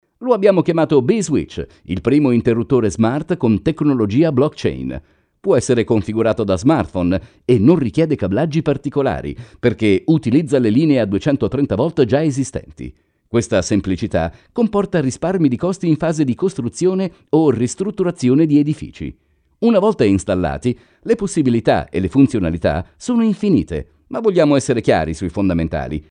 特点：大气浑厚 稳重磁性 激情力度 成熟厚重
风格:浑厚配音